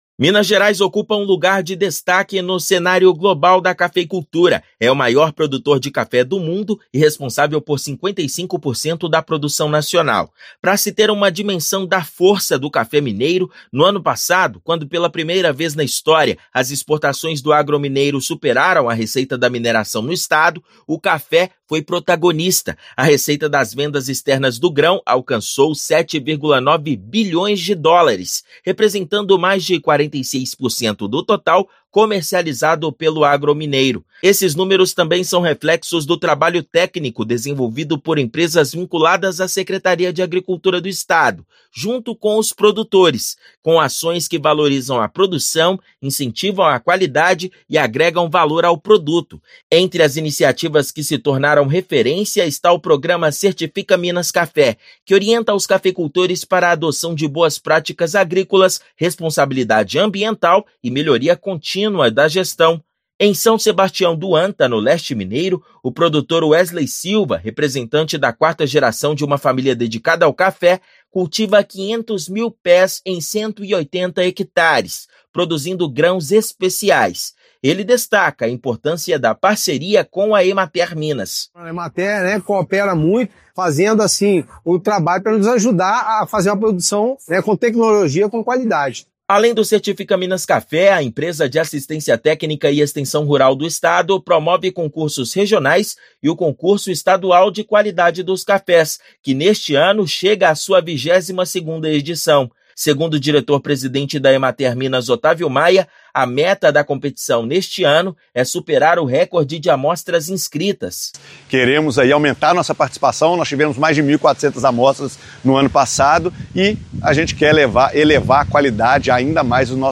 [RÁDIO] Concurso Estadual de Qualidade dos Cafés está com inscrições abertas até o 05/9.
A iniciativa, promovida pela EMATER, faz parte das ações para fortalecer o setor. Ouça matéria de rádio.